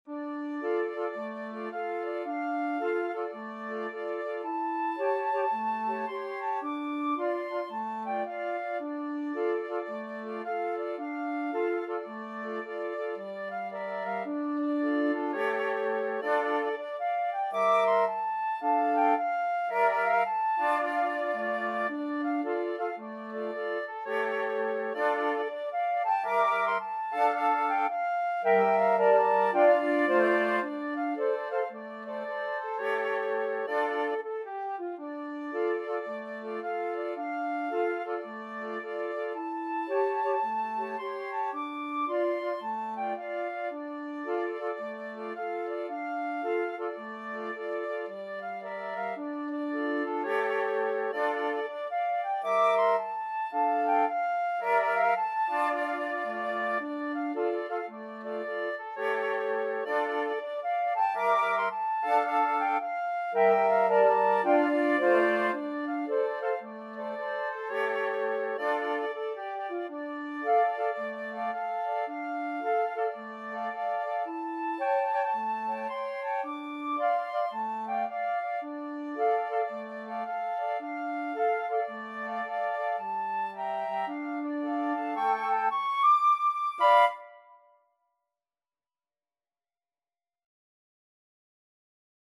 Moderate Gospel , Swung = c.110
4/4 (View more 4/4 Music)